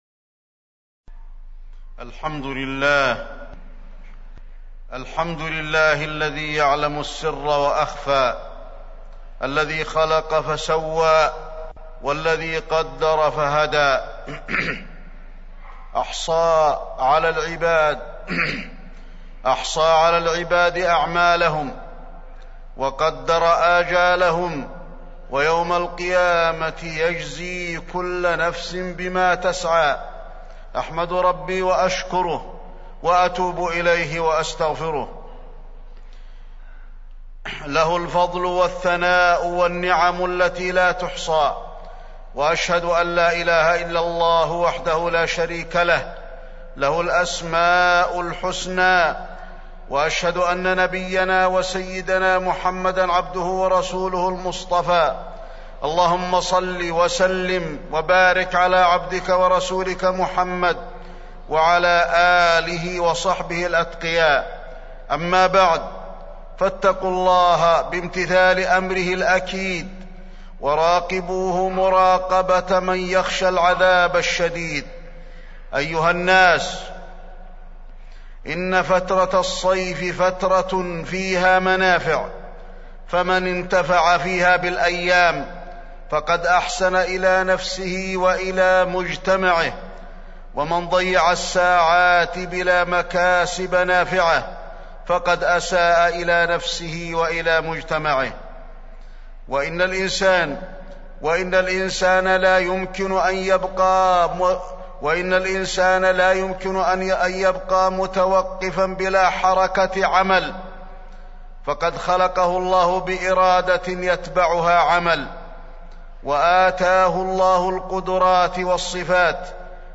تاريخ النشر ٢٤ جمادى الأولى ١٤٢٦ هـ المكان: المسجد النبوي الشيخ: فضيلة الشيخ د. علي بن عبدالرحمن الحذيفي فضيلة الشيخ د. علي بن عبدالرحمن الحذيفي اغتنام الأوقات